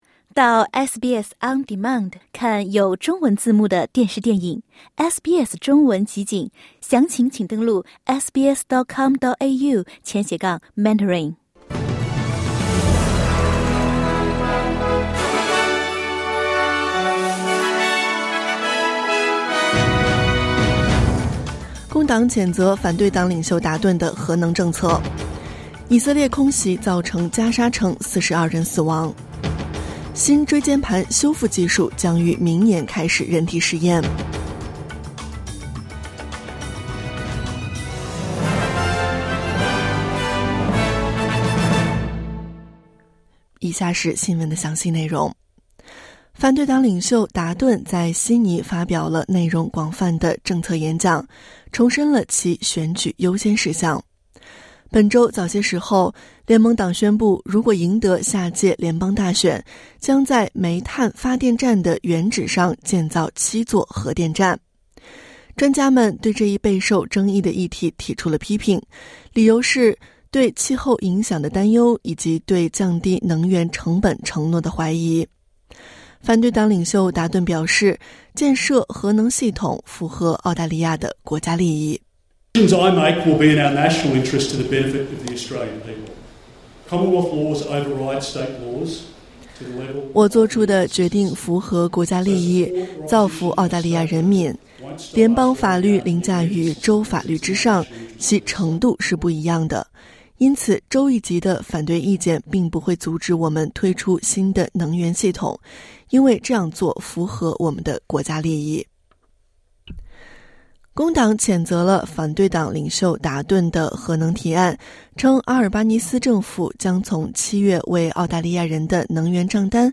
SBS早新闻（2024年6月23日）